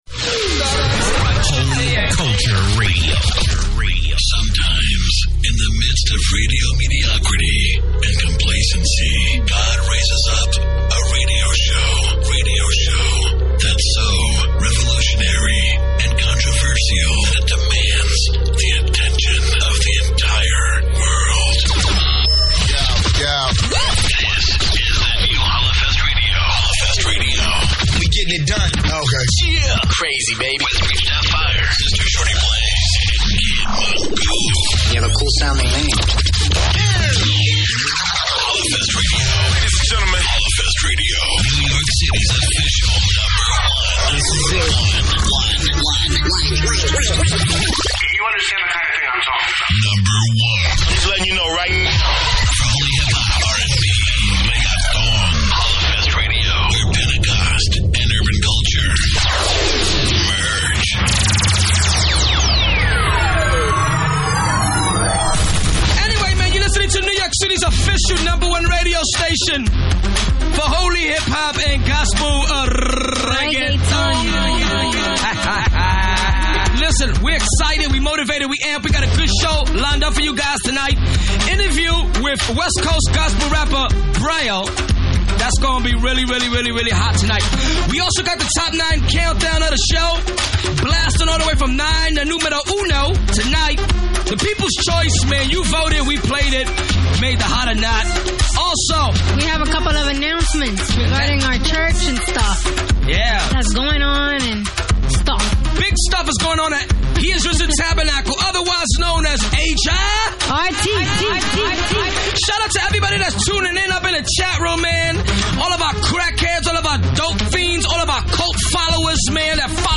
Top 9 Countdown
Spanish Reggaeton
Sermon Topic - Should Restitution accompany reconciliation??? 5. DJ Mixset
Hot or Not - Live Calls 7. Interview